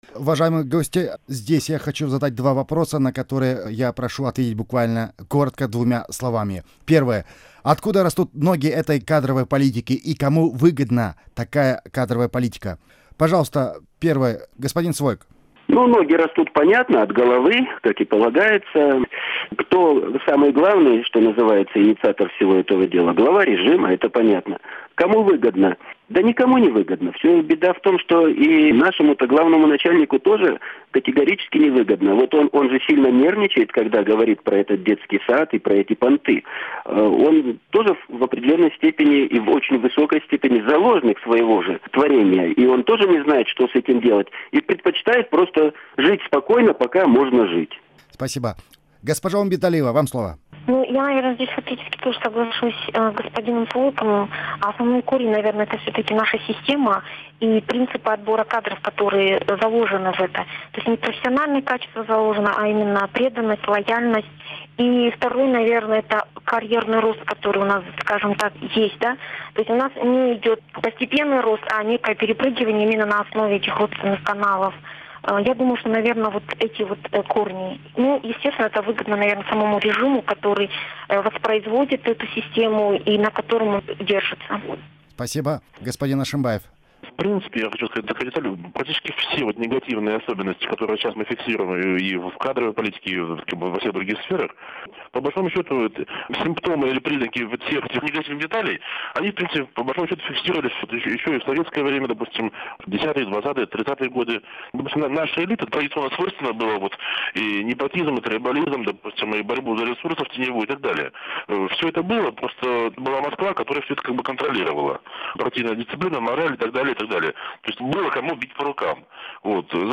Аудиозапись круглого стола. Вторая часть